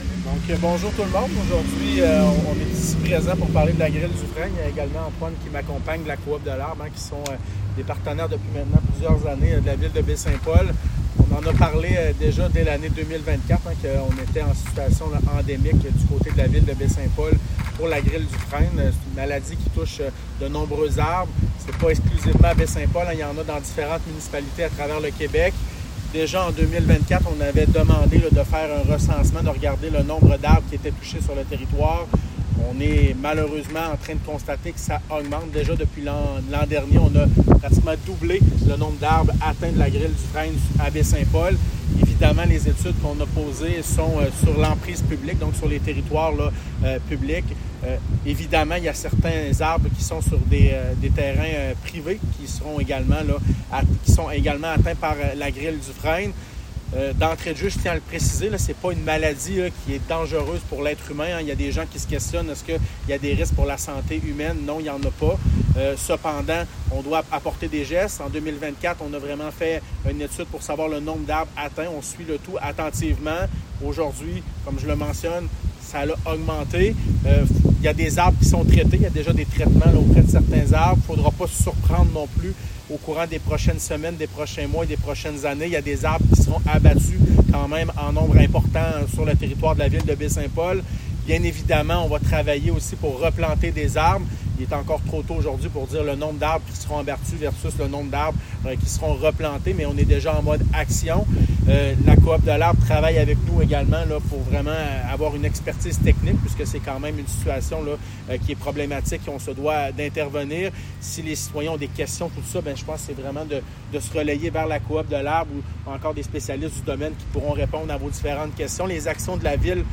Point de presse du maire Pilote sur l'agrile du frêne.